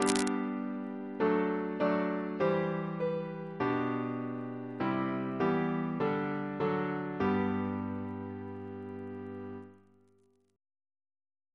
Single chant in G Composer: Leopold Lancaster Dix (1861-1935) Reference psalters: ACB: 63